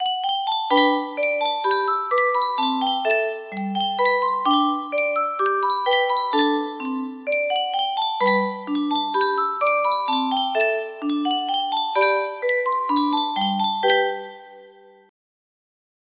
Yunsheng Music Box - Danny Boy Y199 music box melody
Full range 60